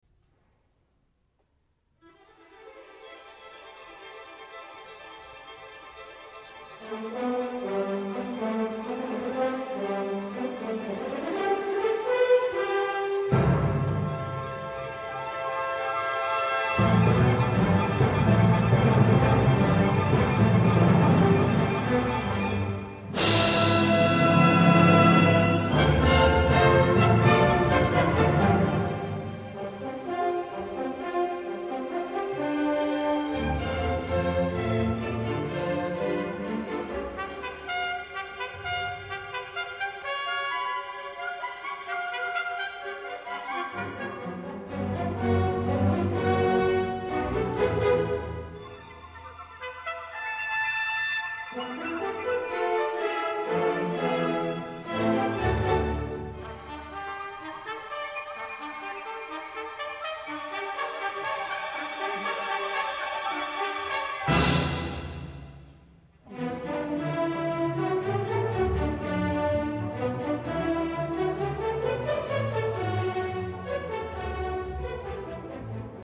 下記のMP3ファイルはブロードバンド用ステレオ音声です。
(128Kbps, 44KHz, Stereo Sound MP3 files)